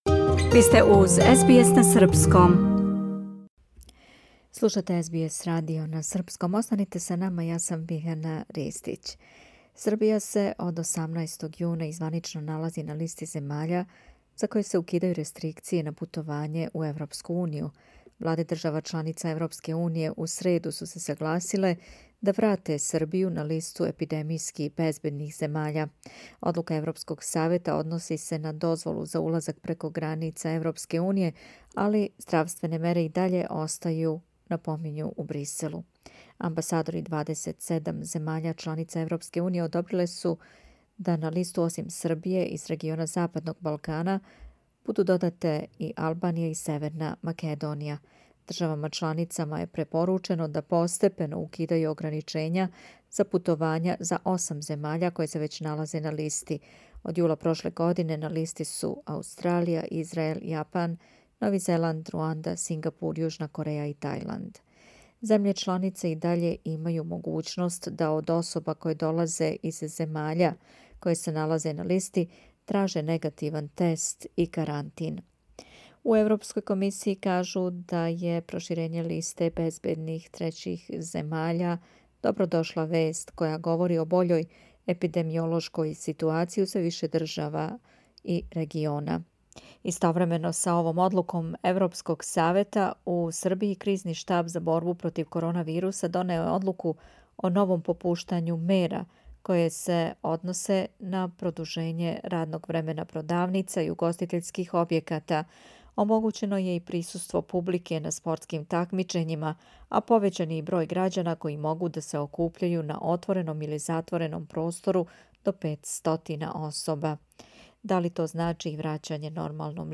у разговору